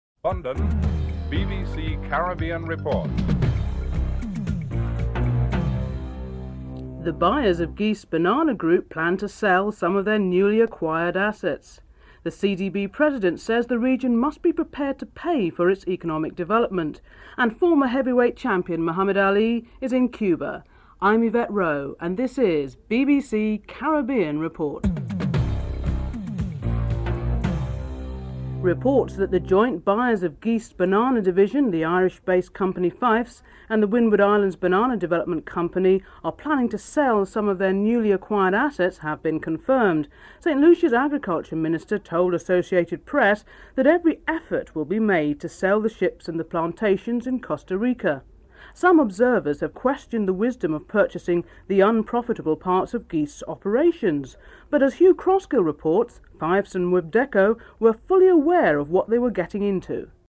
1. Headlines (00:00-00:28)
Ambassador Jose Gutierrez is interviewed (07:28-08:57))